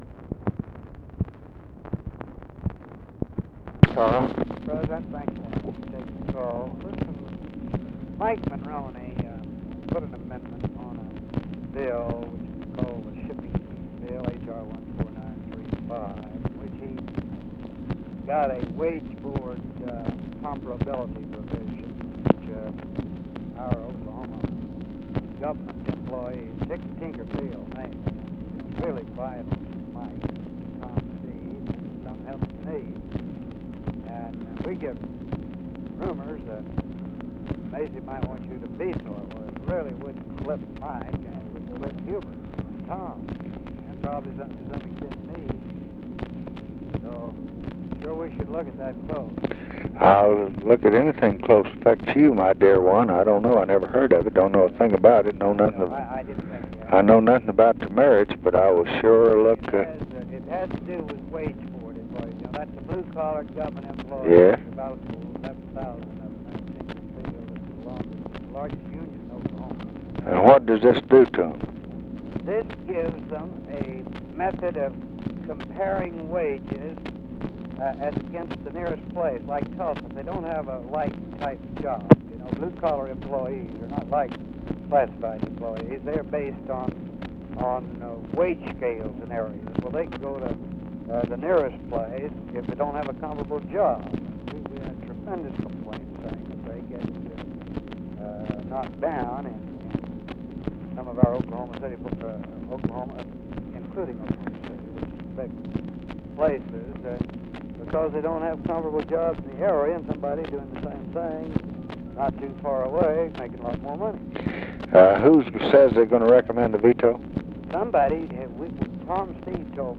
Conversation with CARL ALBERT, October 9, 1968
Secret White House Tapes